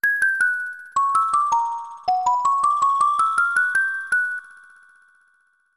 Sound effect from Super Mario RPG: Legend of the Seven Stars